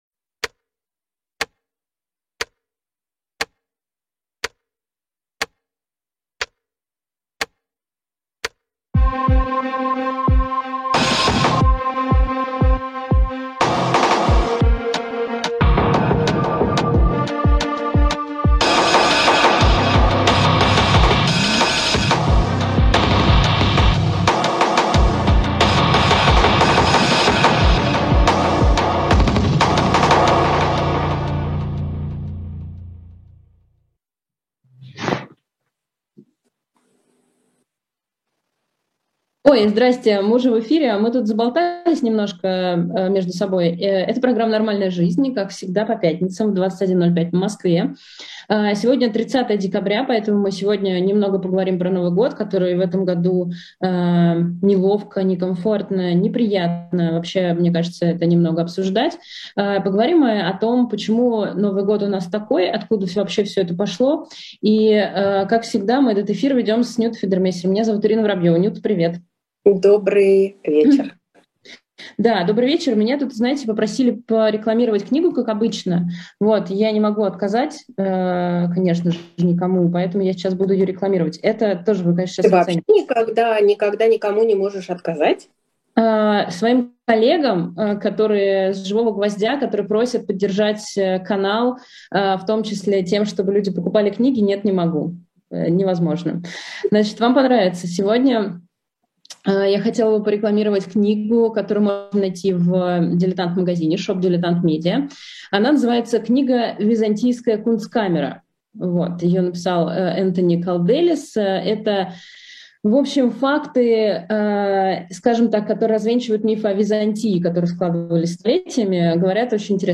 Ведущие: Ирина Воробьёва и Нюта Федермессер.